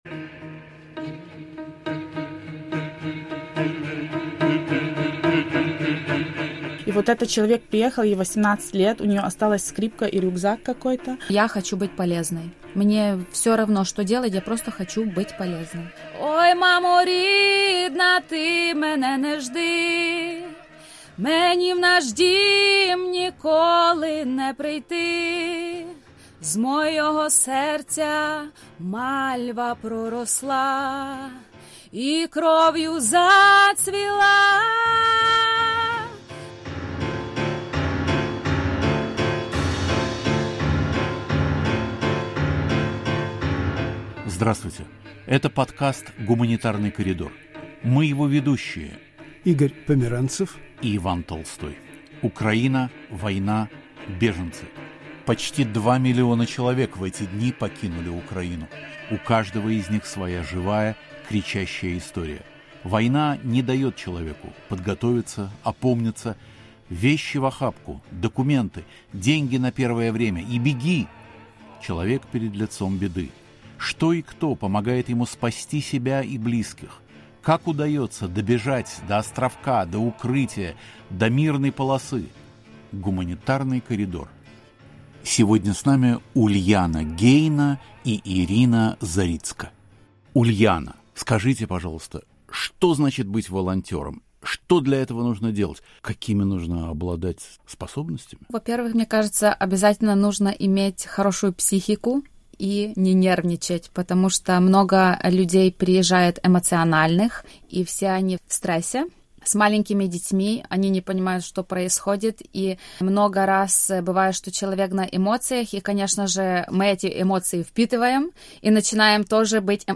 В студии "Свободы"